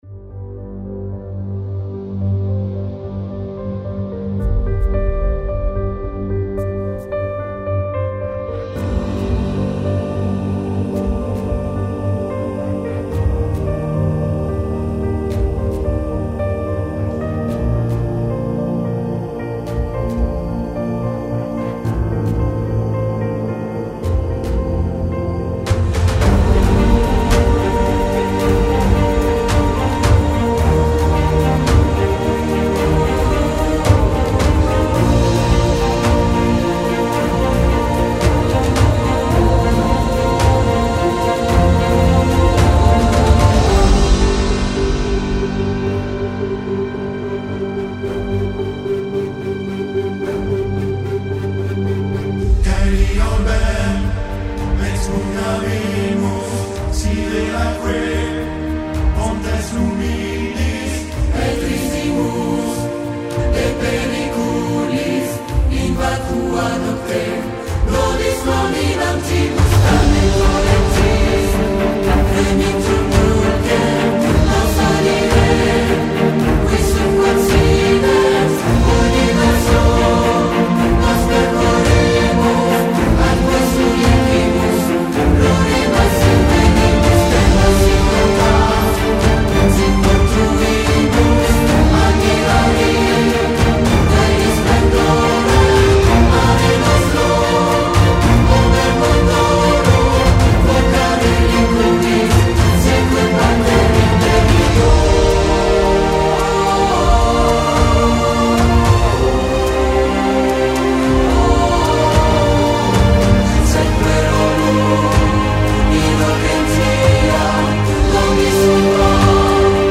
Epic Choir
Sopranos
Altos
Tenors
Basses